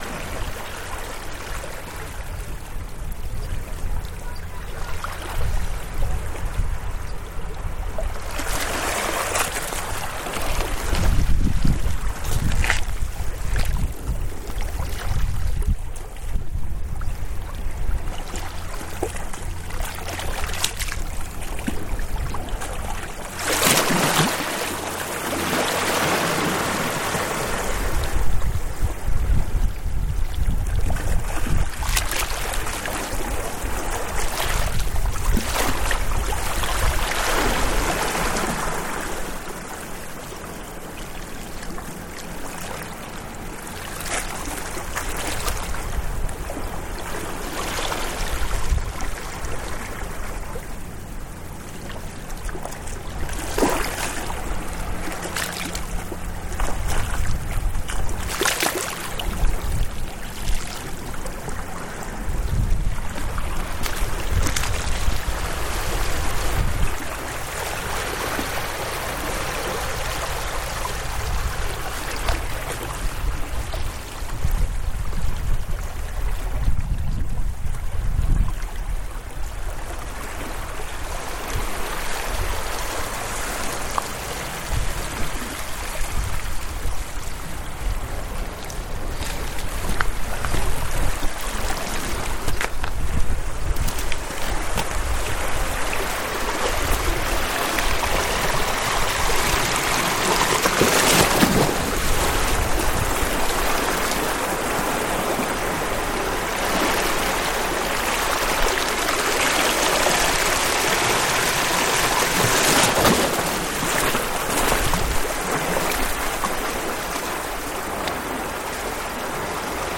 Oban waves, Isle of Staffa